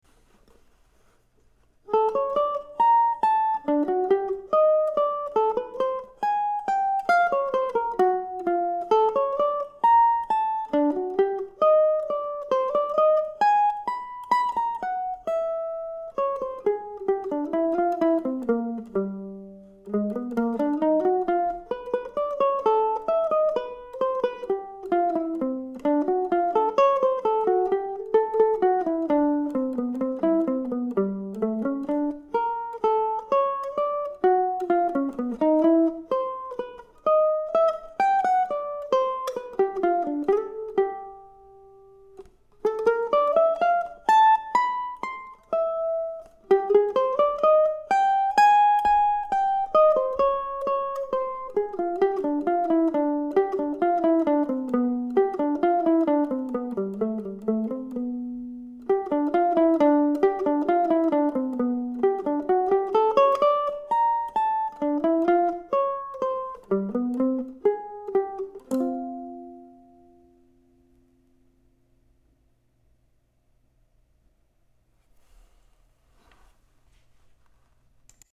Bagatelle for Solo Mandolin, no. 1